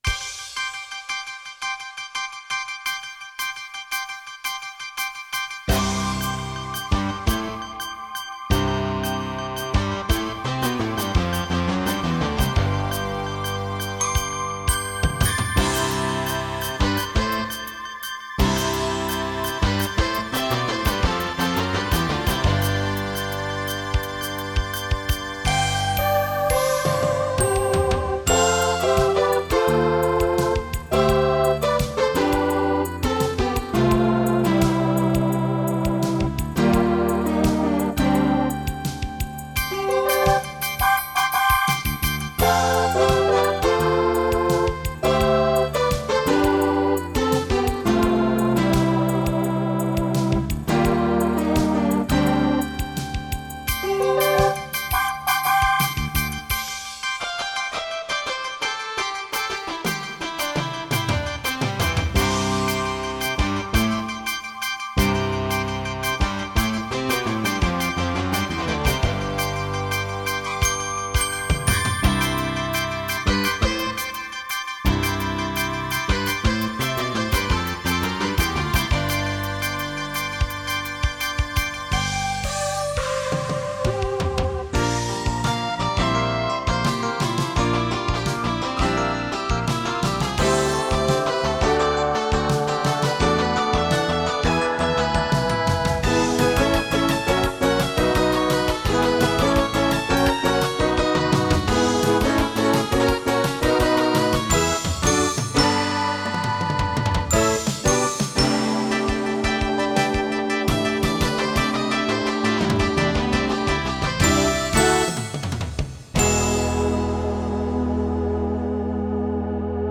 GM song 연주하기
오디오 인터페이스는 Mackie Onyx Producer 사용.